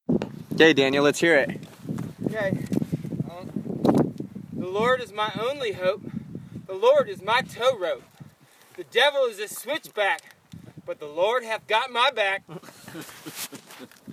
a brief poem .